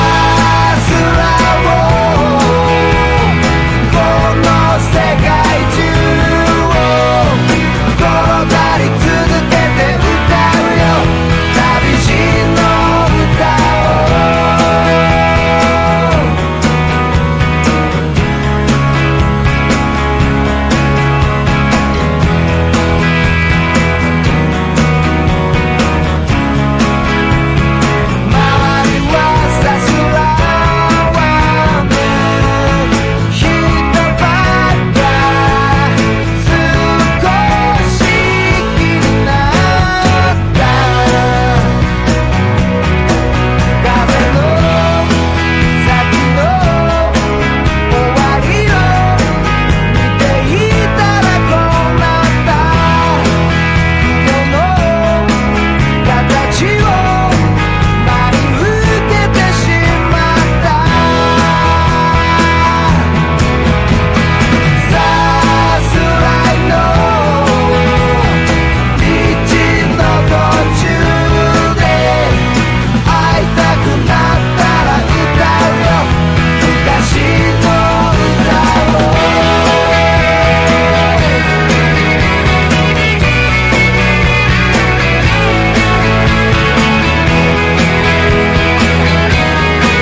90-20’S ROCK